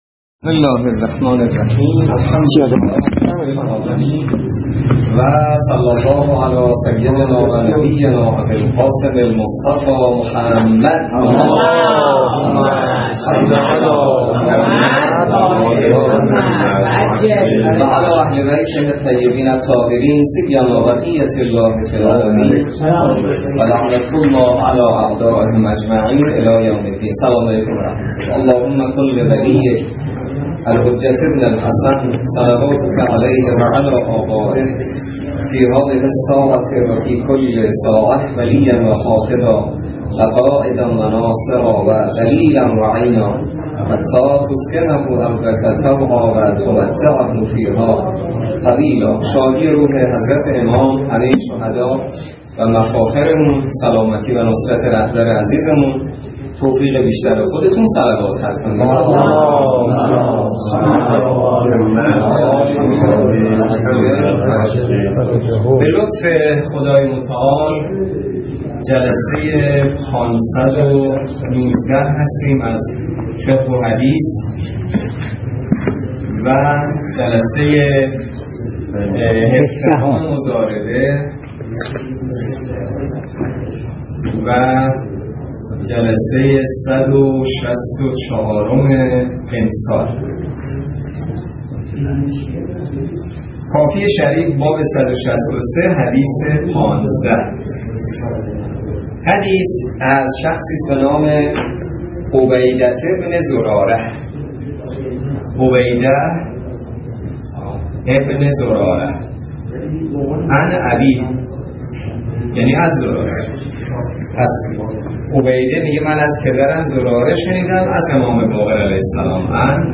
روایات ابتدای درس فقه موضوع: فقه اجاره - جلسه ۱۷